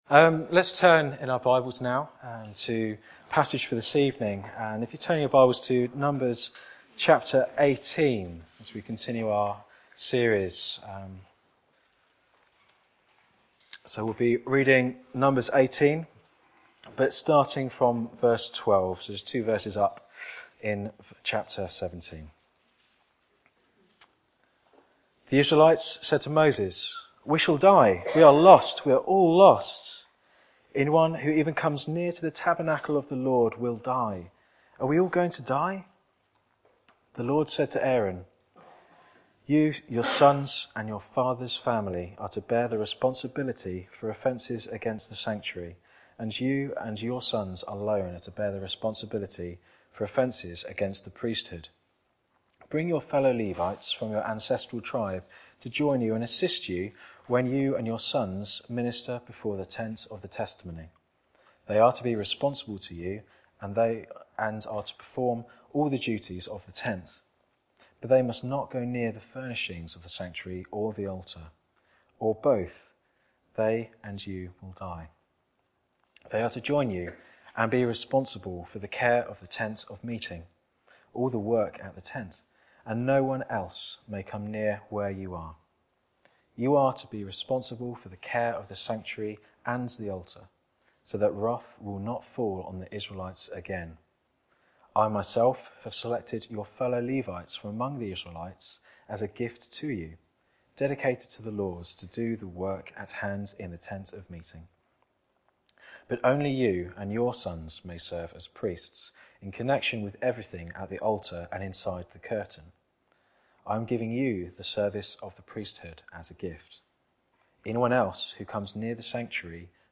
Back to Sermons Fear of God revealed